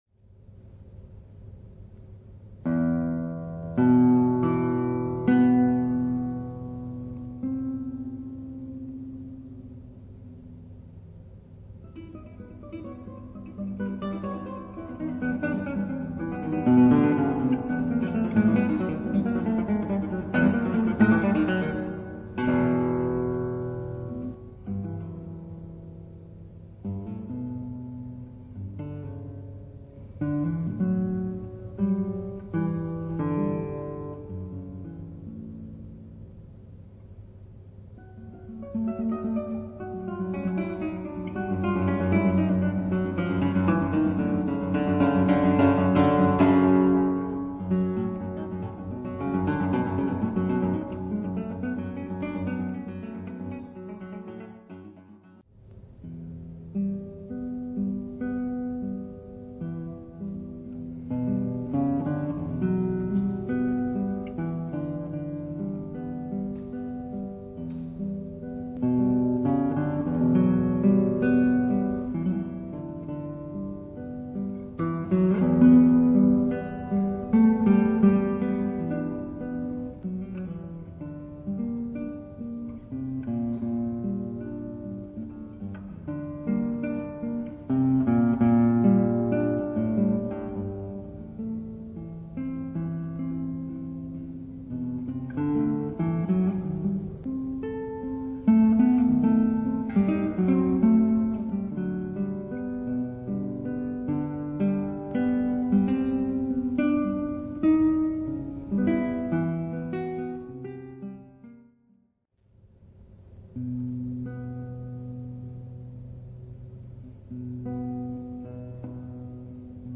Per chitarra con allegato un CD